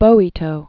(bōē-tō), Arrigo 1842-1918.